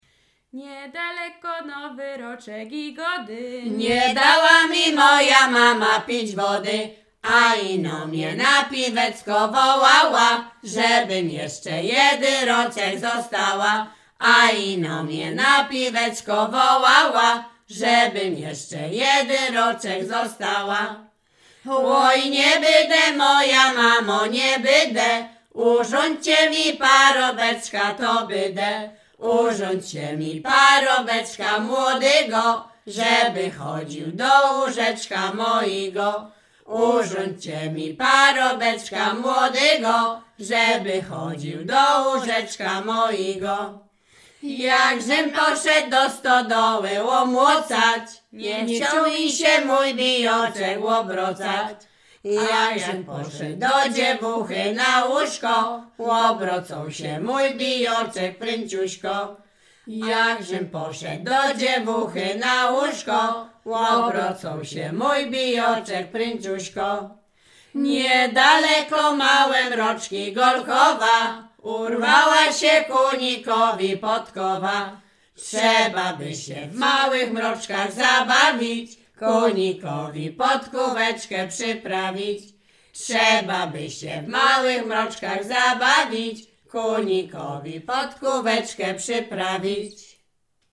Śpiewaczki z Mroczek Małych
województwo łodzkie, powiat sieradzki, gmina Błaszki, wieś Mroczki Małe
liryczne miłosne żartobliwe przyśpiewki